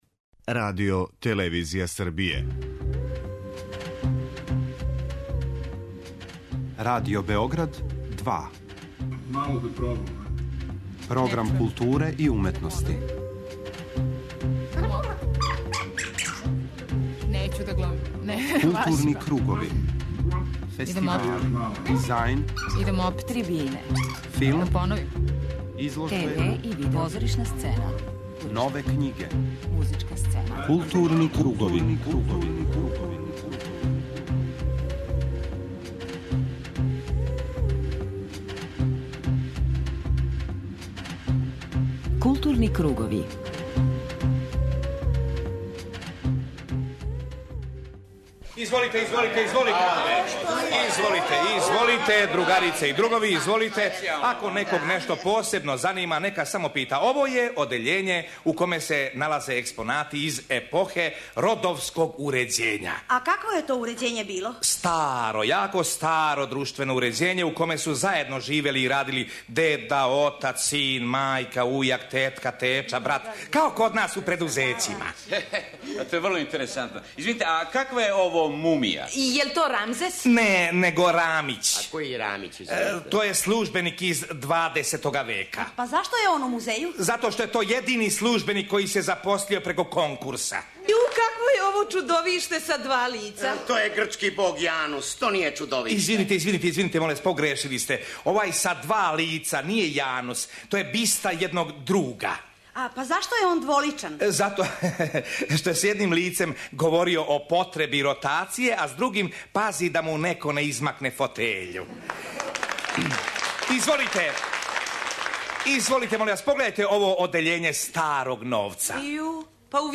У 'Културним круговима' који се, поводом 90 година Радио Београда, емитују из Крагујевца покушаћемо да представимо културни идентитет овог града.